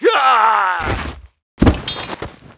带男声的死亡倒地2zth070518.wav
通用动作/01人物/02普通动作类/带男声的死亡倒地2zth070518.wav
• 声道 單聲道 (1ch)